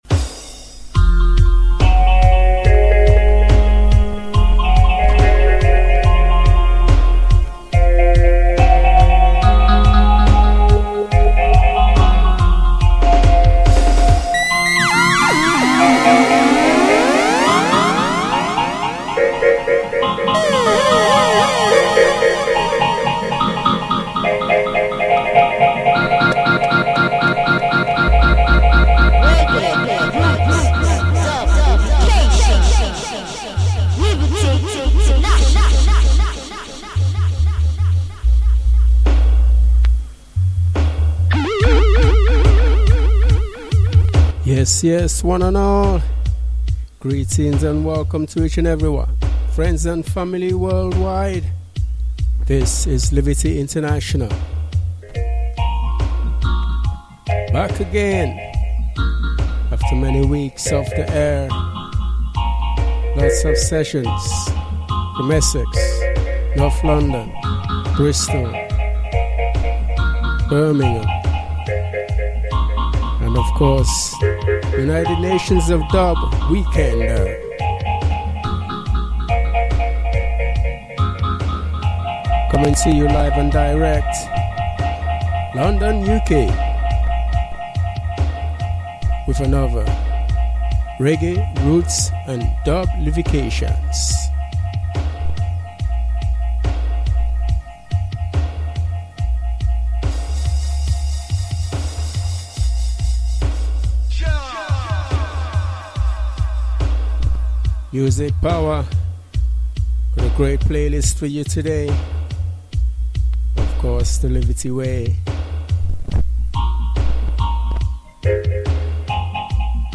new and old tunes